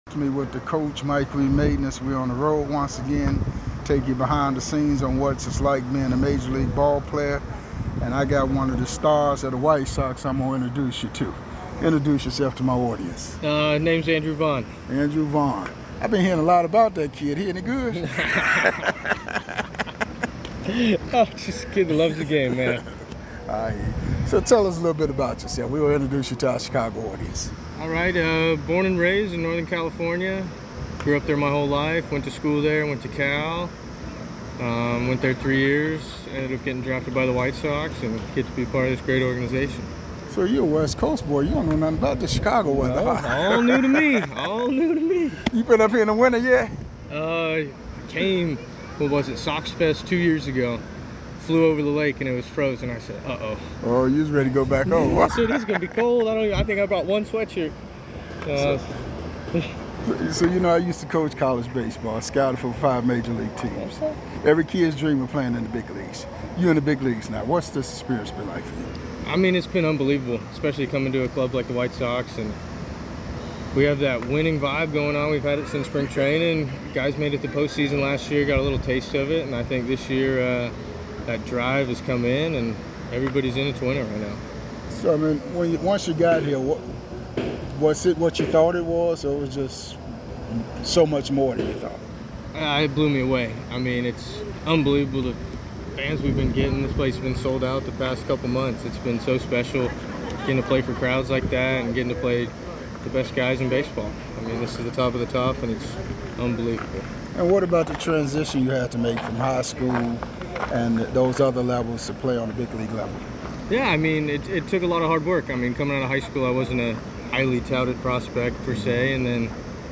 Taking you behind the scenes full uncut and unedited MLB interviews with past and present players